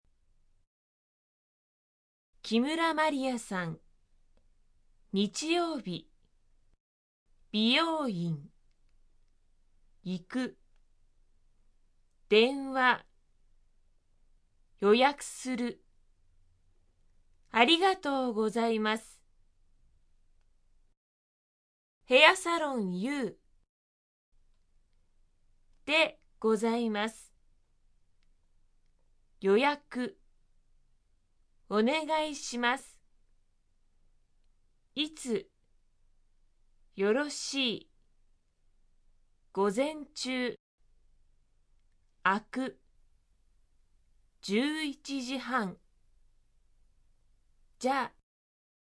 会話例●話す ●聞く ○読む ○書く 　希望・願望を述べる